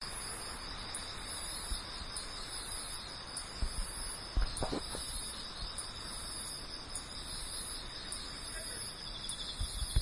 描述：Aprèsmidi d'août，ville，quartier calme。对话引起了三分之一的恐惧。 Voitures et chiens au loin。 八月，下午，安静的小镇区。三个臭虫在聊天。汽车和狗在背景中。
标签： 错误 宁静的镇 昆虫 夏天
声道立体声